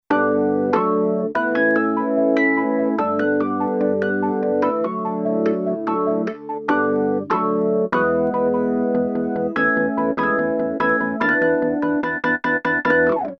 Disk PSL9046 "Hammond"